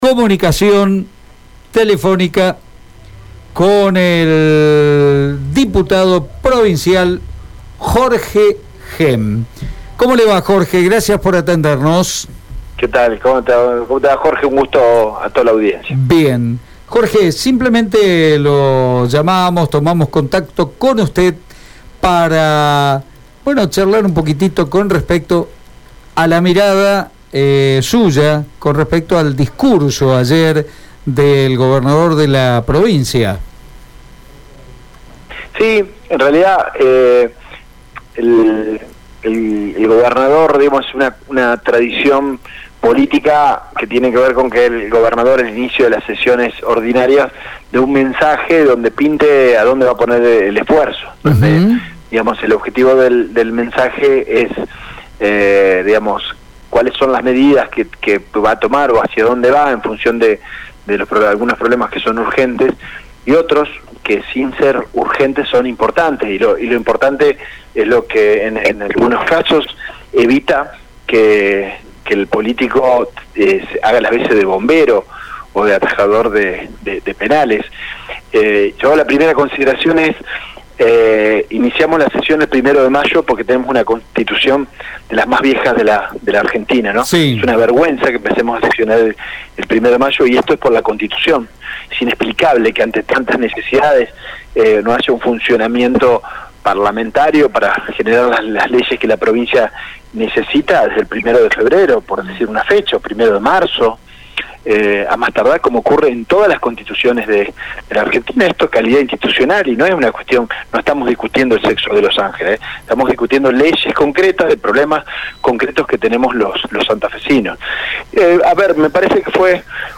Jorge Henn, Diputado Provincial, habló en Radio EME sobre el discurso que dio ayer el gobernador Miguel Lifschitz cuando comenzaron las sesiones.